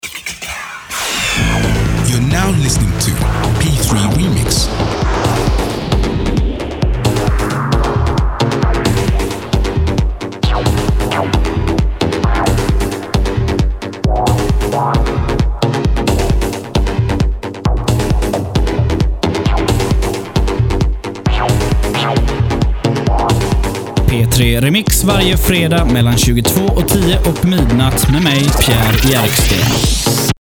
Här nedan hör du lite reklam för programmen.